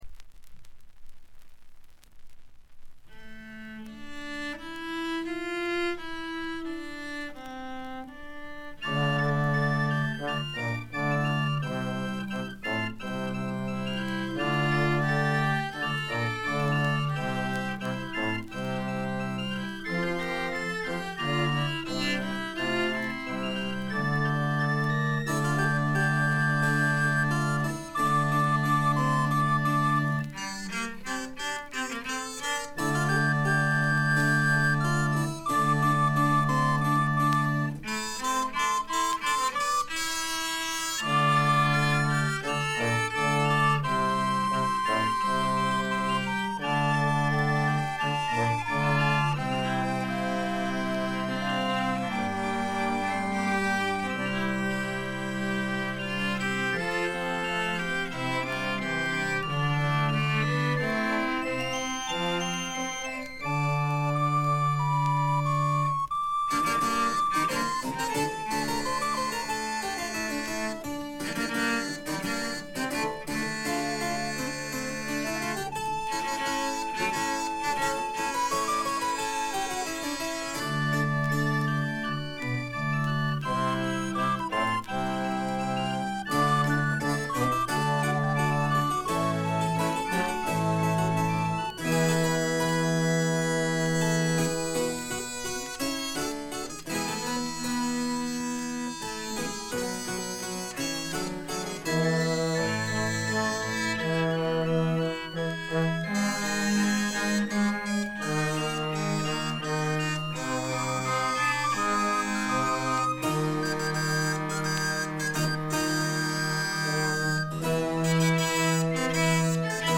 ホーム > レコード：英国 フォーク / トラッド
これ以外も軽い周回ノイズ、チリプチ、プツ音等多め大きめです。
試聴曲は現品からの取り込み音源です。